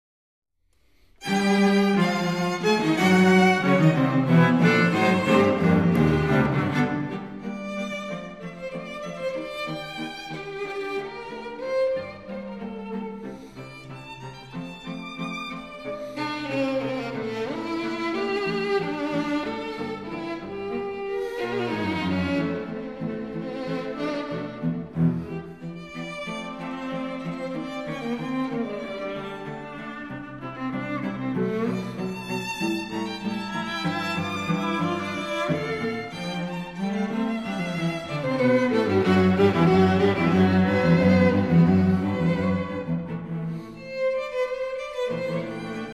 Streichtrio No. 1
Violine
Viola
Violoncello